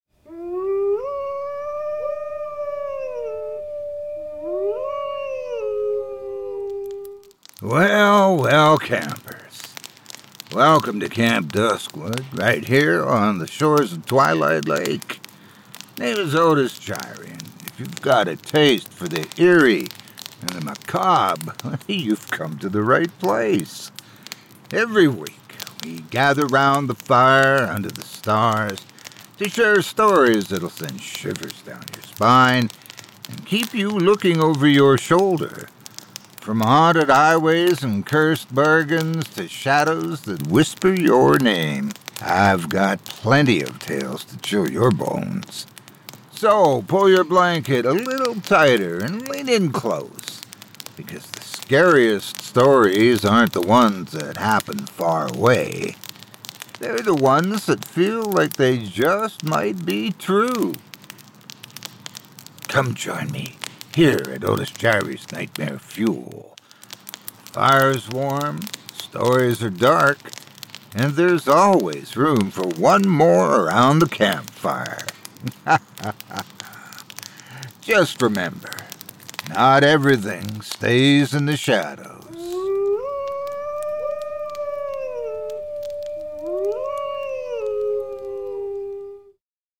Trailer
blending dark humor, atmospheric storytelling, and plenty of scares.